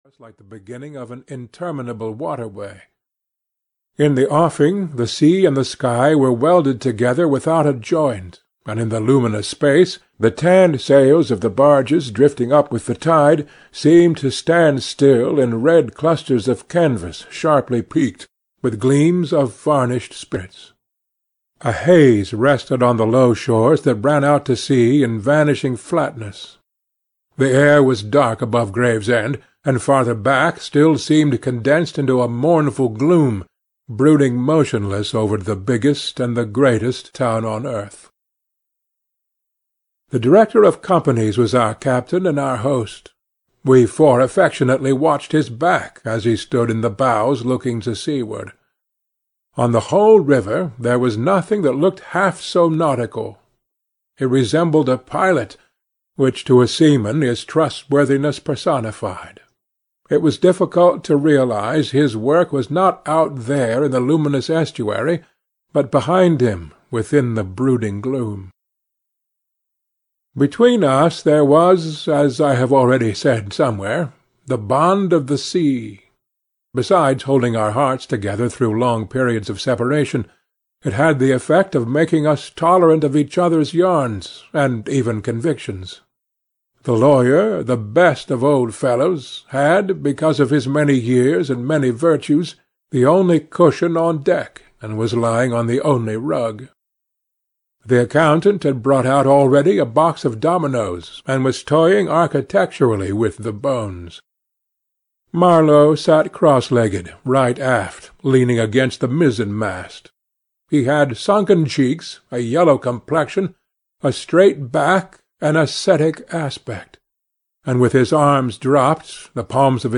Heart of Darkness (EN) audiokniha
Ukázka z knihy